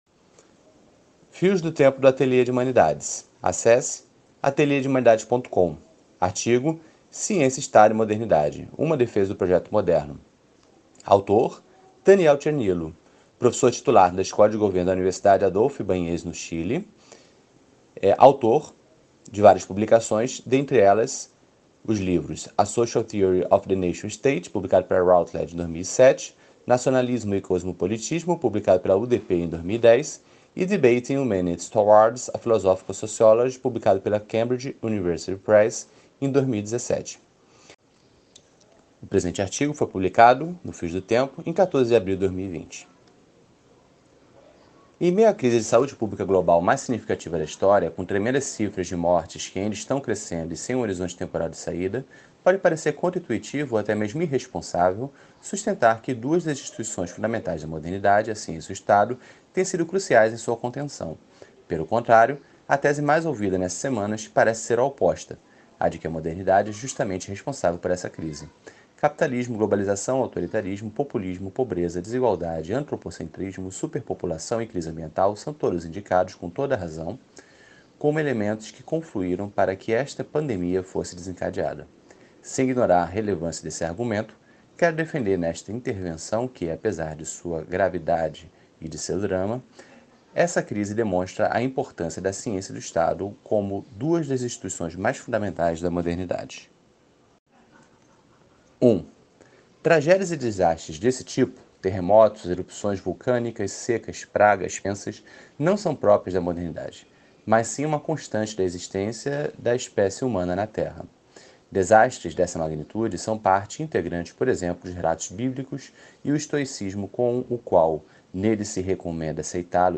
áudio-leitura